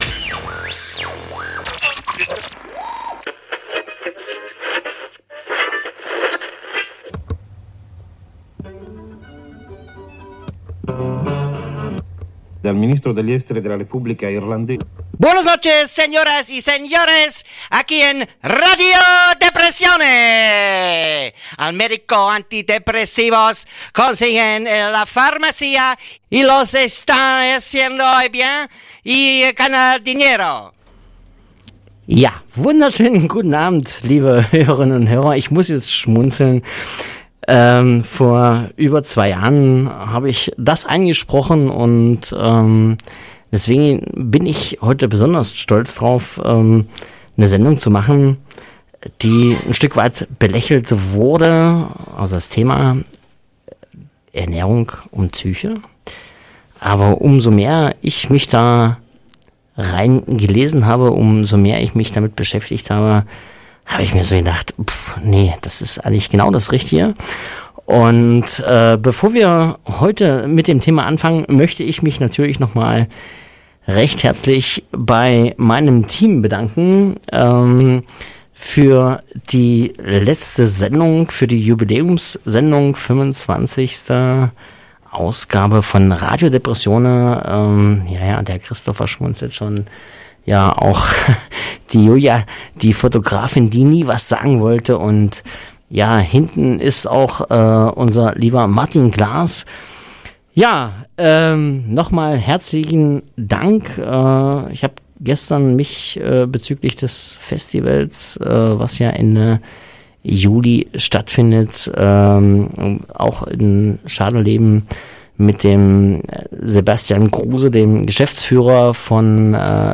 Es geht um psychische Störungen und Erkrankungen, Behandlungsmöglichkeiten und Anlaufpunkte für Betroffene. Dazu gibt es regelmäßig Interviews mit Fachleuten und Betroffenen, Buchtipps und Umfragen zu bestimmten Themen.